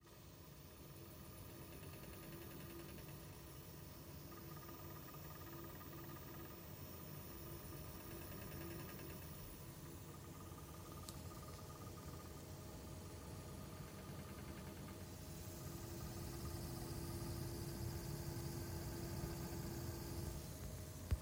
Netzteil klackert
Hallo, mir ist heute aufgefallen das mein Seasonic Focus GX 750W Gold Netzteil rattert/klackert ich habe bereits 2 andere Beiträge zu diesem Thema gelesen...